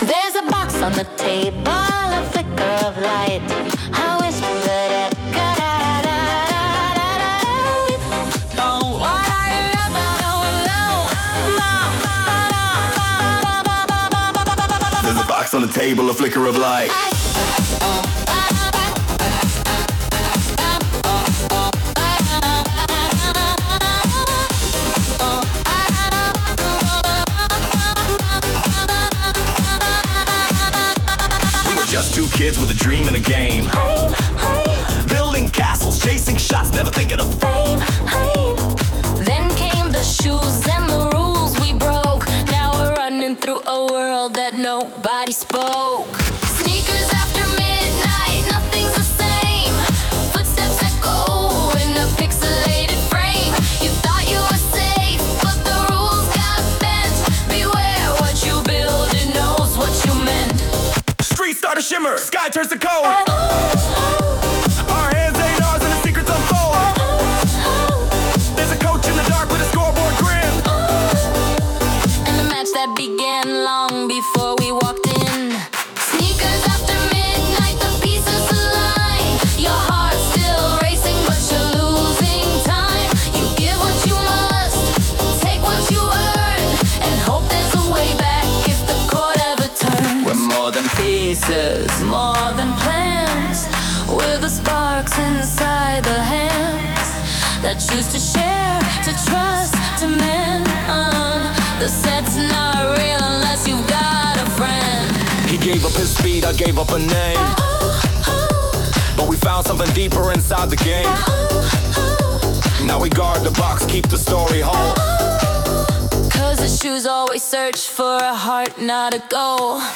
Perfect for setting the mood during each thrilling chapter.